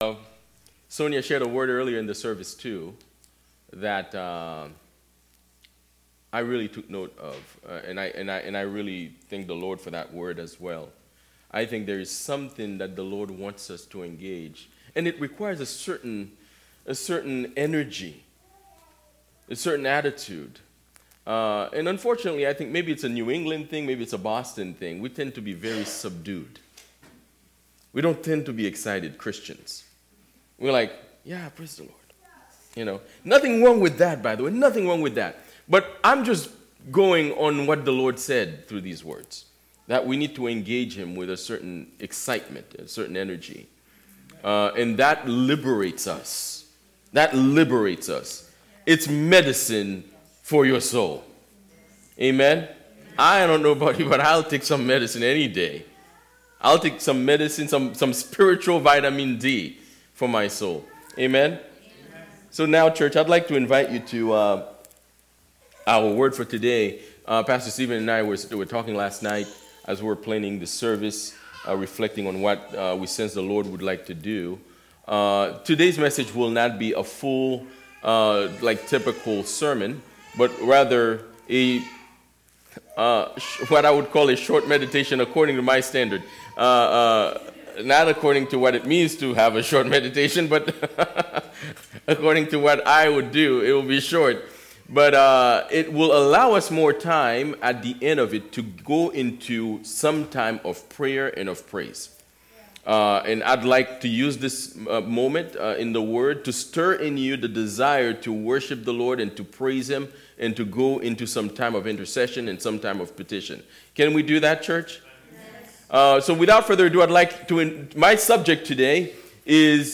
Sermons by Fellowship Church Dedham